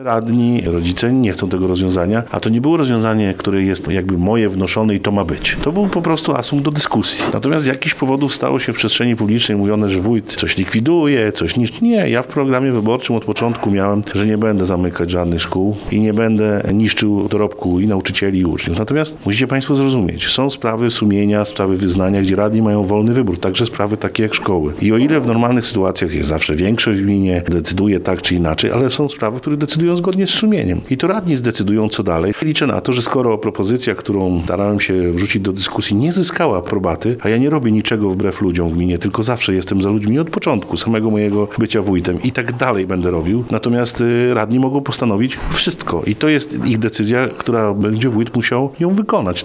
– Propozycja miała być przyczynkiem do dyskusji. Członkowie komisji powinni bliżej przyjrzeć się sprawie i zdecydować zgodnie z własnym sumieniem – stwierdził po posiedzeniu wójt Tomasz Osewski.